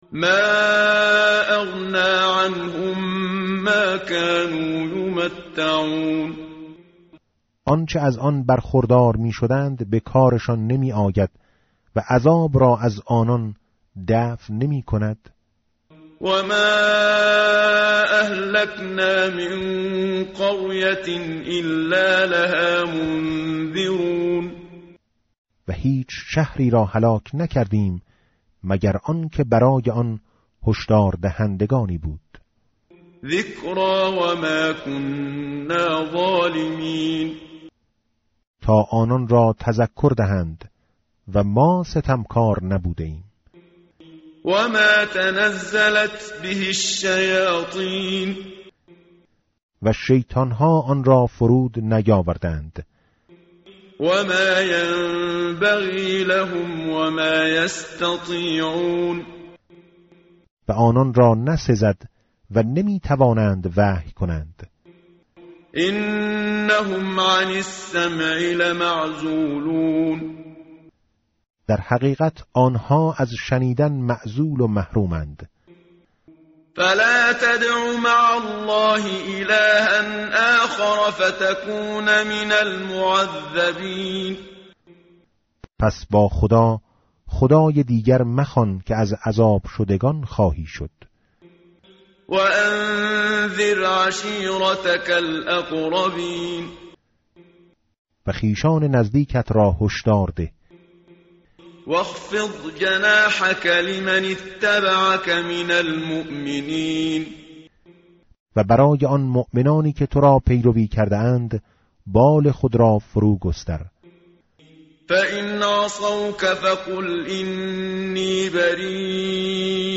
متن قرآن همراه باتلاوت قرآن و ترجمه
tartil_menshavi va tarjome_Page_376.mp3